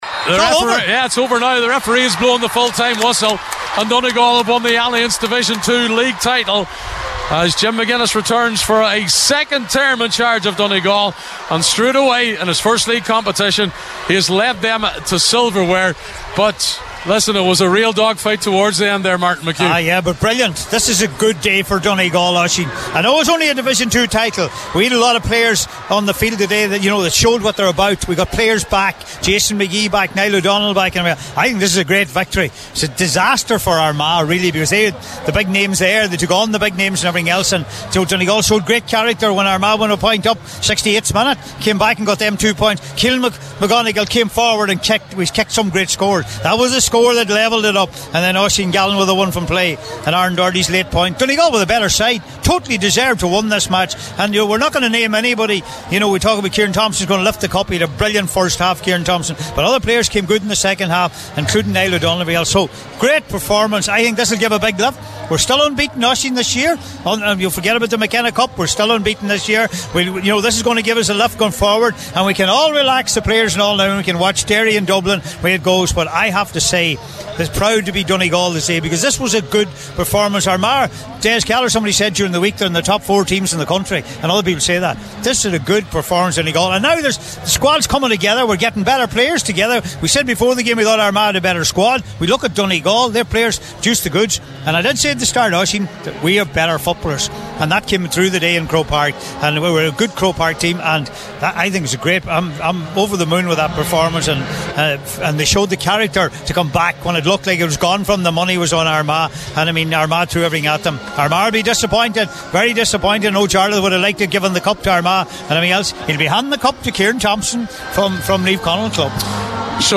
reported live from Croke Park at full time…